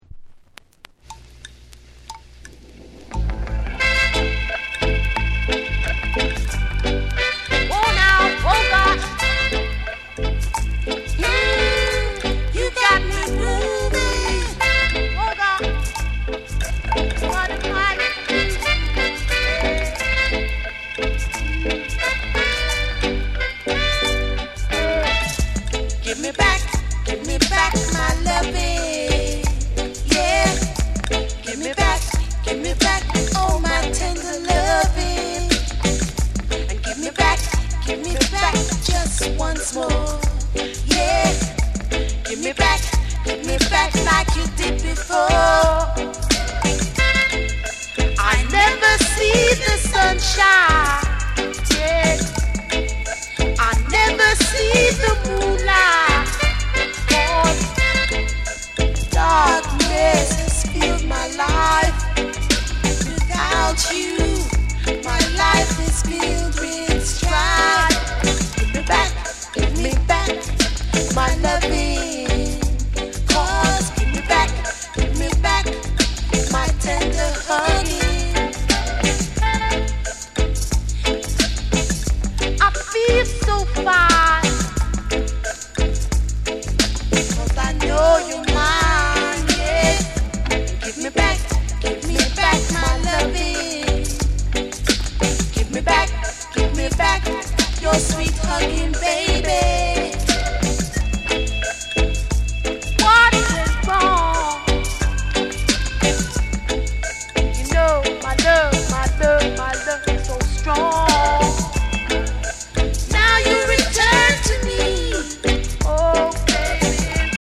ミッドテンポのルーツ・リディムに乗せて、どこか儚げでメロウなハーモニーが心に染みる
REGGAE & DUB